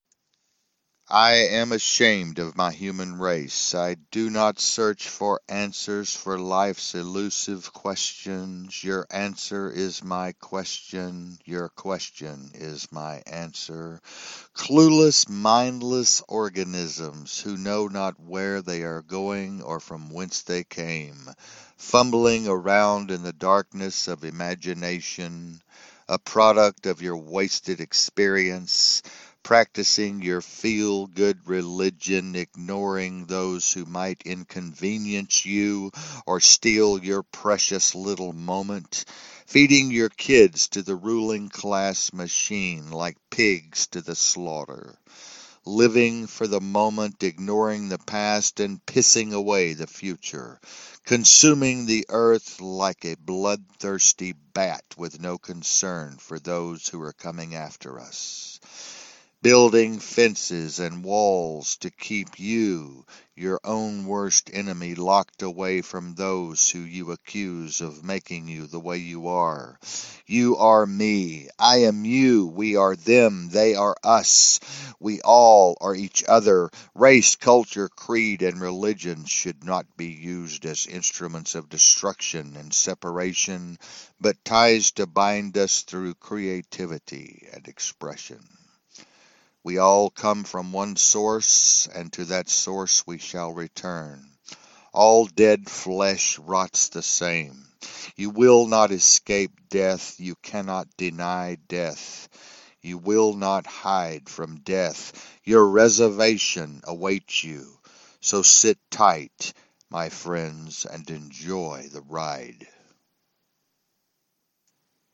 A Spoken Word Piece